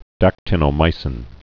(dăktə-nō-mīsĭn)